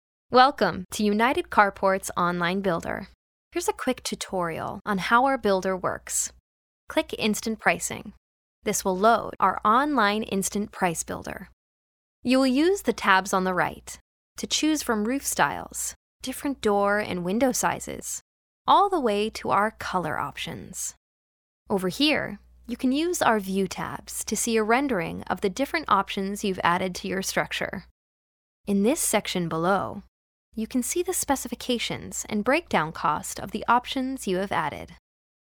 Female Voice Over, Dan Wachs Talent Agency.
Warm, Genuine, Conversational.
eLearning